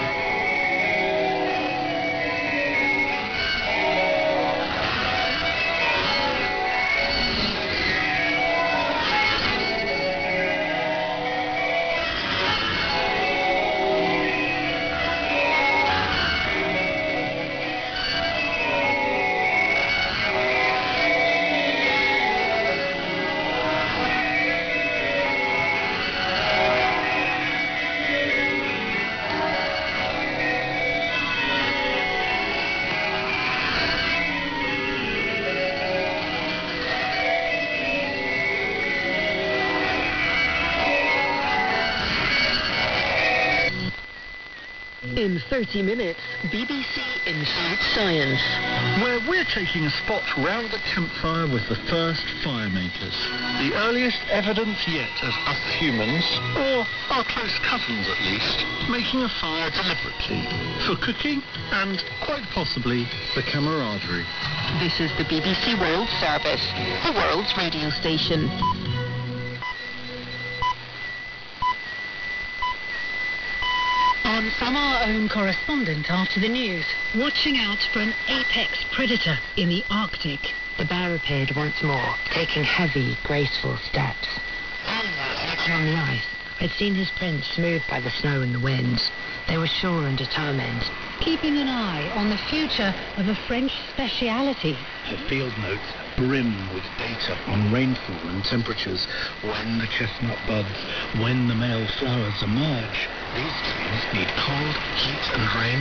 BBC Interval Signals – Then and Now
I also logged them using their Bow Bells interval signal on December 28, 2025 at 2358 UTC on 6155 kHz while listening on a Kiwi SDR in Thailand.